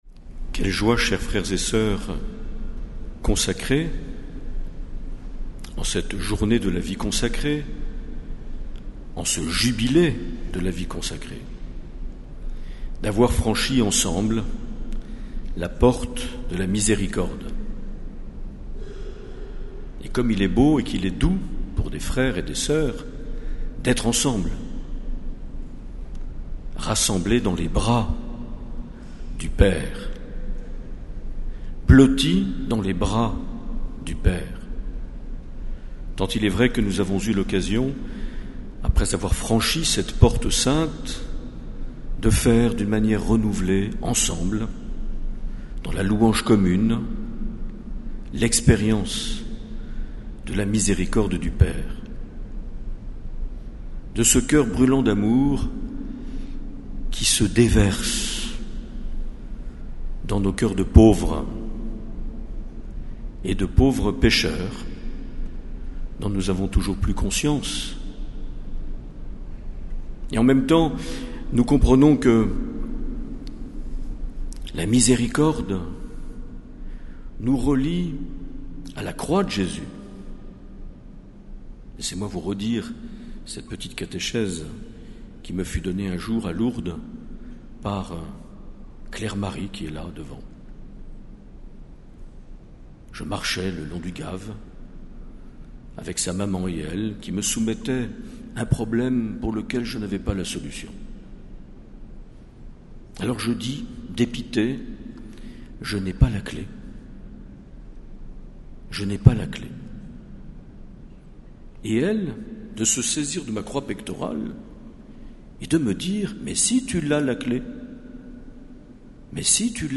2 février 2016 - Cathédrale de Bayonne - Clôture de l’année de la Vie Consacrée
Accueil \ Emissions \ Vie de l’Eglise \ Evêque \ Les Homélies \ 2 février 2016 - Cathédrale de Bayonne - Clôture de l’année de la Vie (...)
Une émission présentée par Monseigneur Marc Aillet